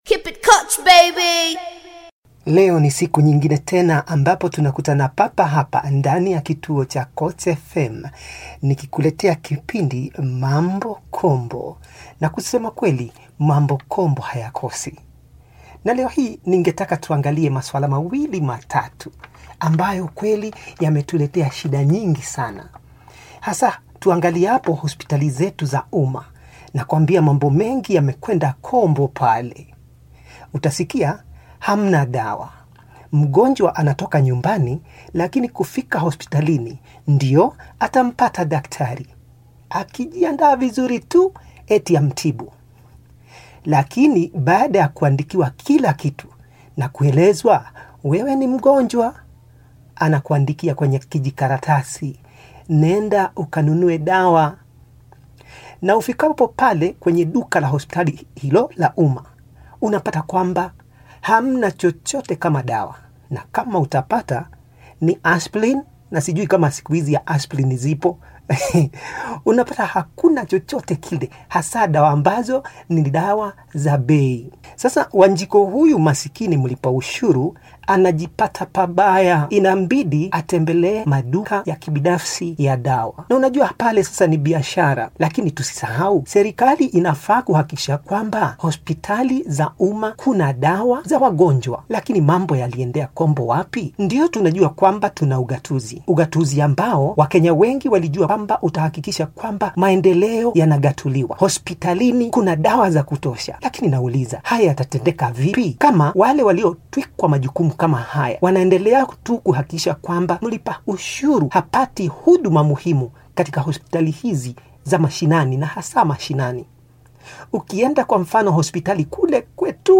Koch FM talk show on deplorable state of health services